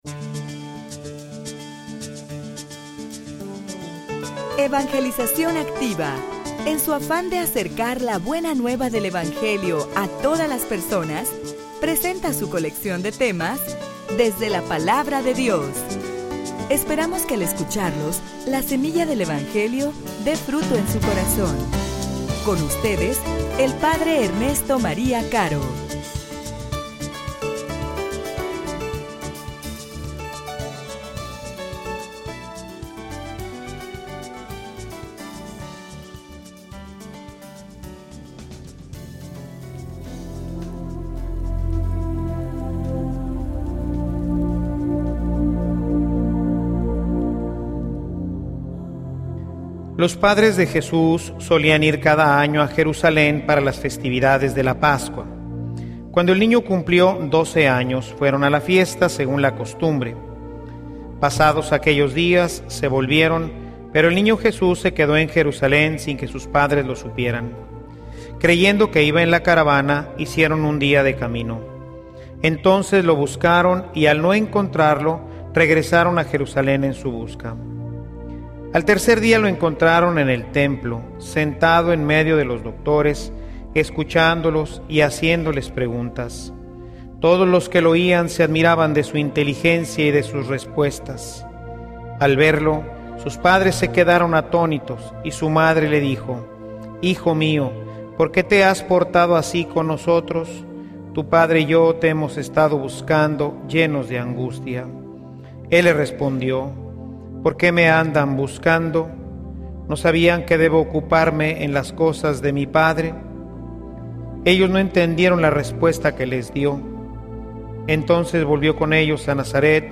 homilia_Instruye_a_tu_familia_en_el_temor_de_Dios.mp3